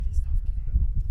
Hier der relevante Teil zugeschnitten, gefiltert und doppelt verstärkt: